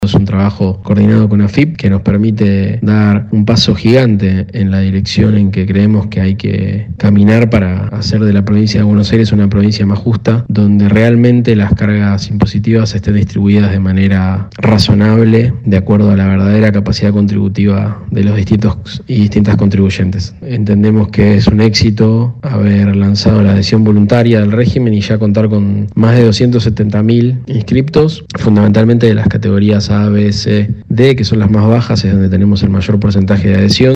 El titular de ARBA, Cristian Girard, aseguró que es un sistema muy beneficioso para bajar la presión impositiva en los sectores que más lo necesitan.